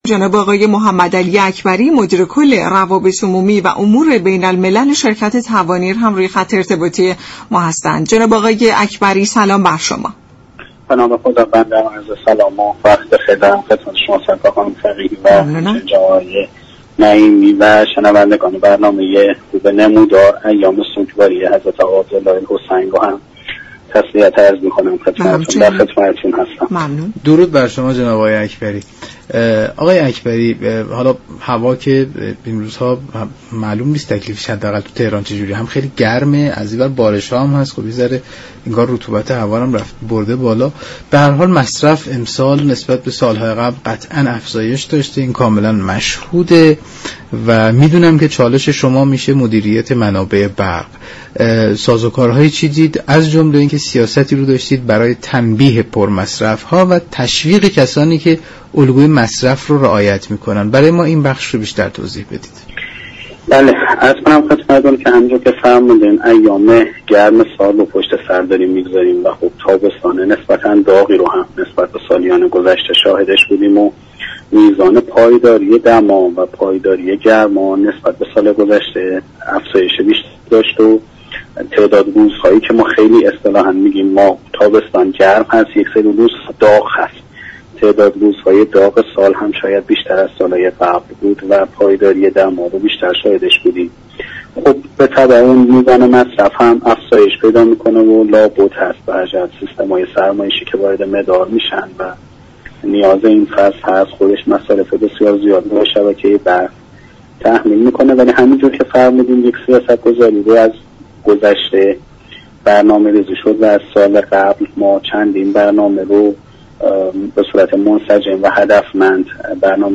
برنامه «نمودار» شنبه تا چهارشنبه هر هفته ساعت 10:20 از رادیو ایران پخش می شود.